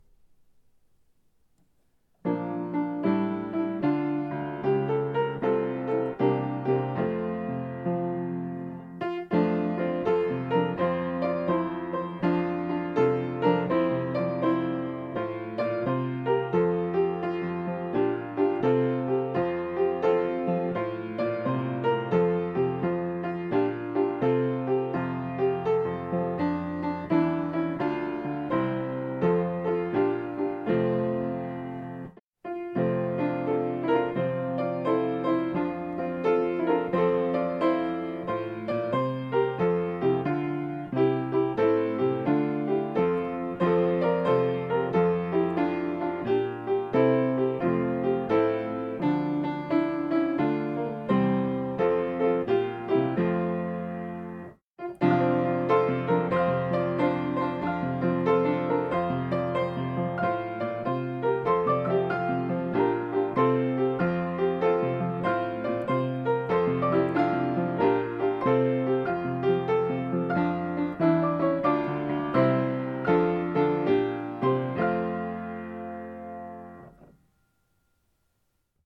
Here are Christmas songs for corporate singing.
piano descant